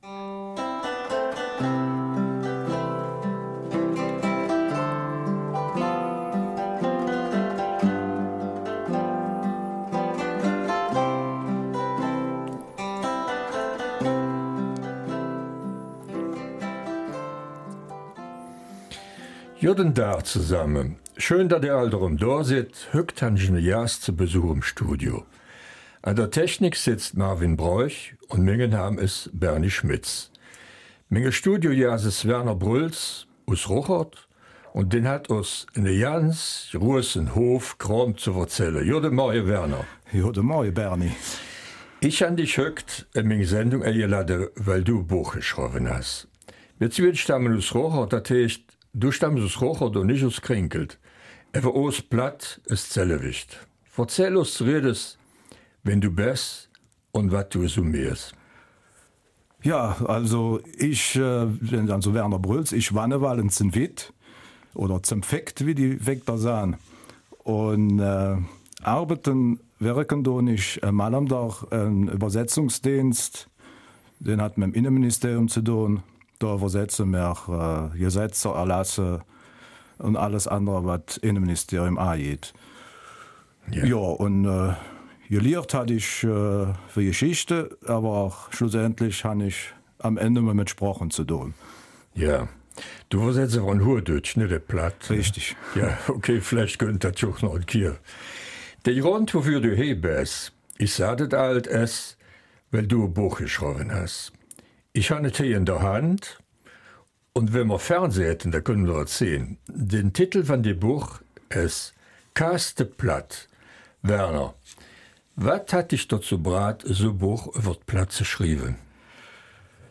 Eifeler Mundart: ''Kasste Platt?'' | Mundart Archiv